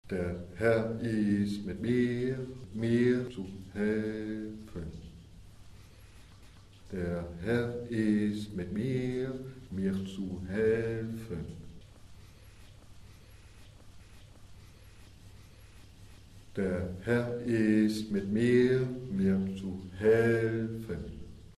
Prononciation
Der Herr ist mit mir, mir zu helfen - chant.mp3